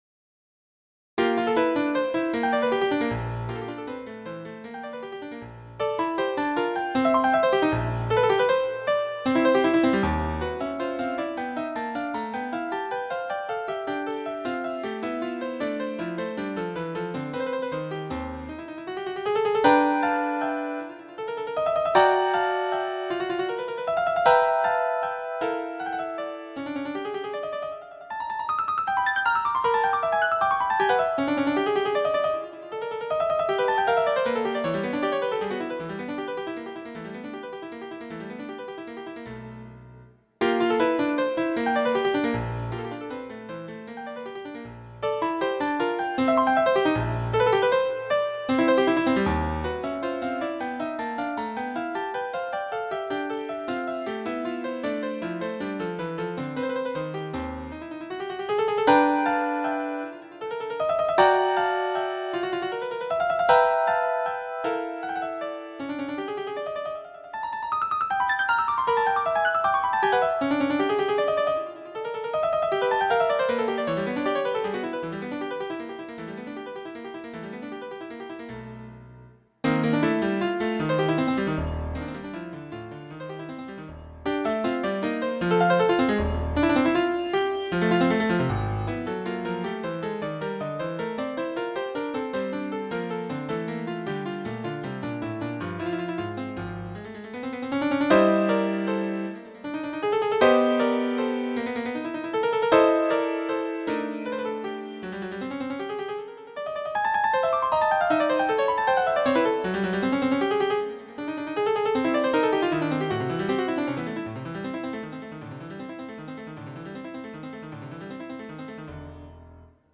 *ETUDE FOR KEYBOARD *
keyboard-etude_1.mp3